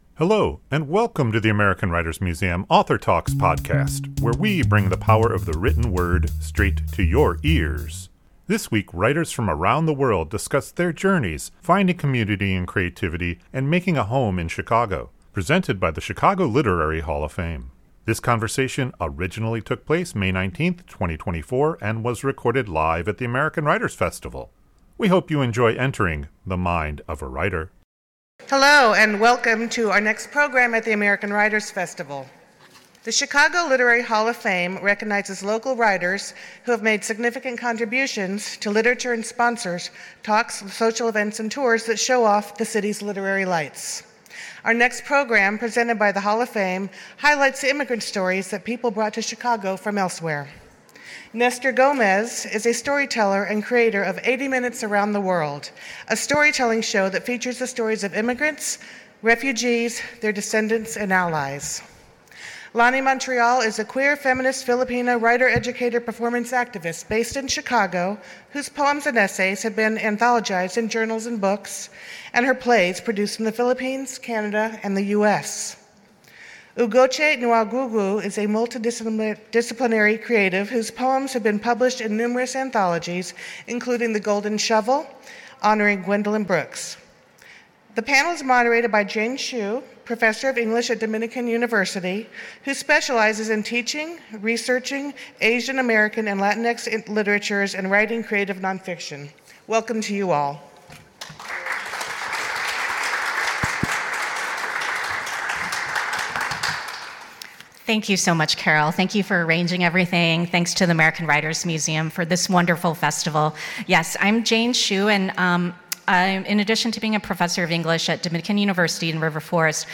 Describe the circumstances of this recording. This conversation originally took place May 19, 2024 and was recorded live at the American Writers Festival.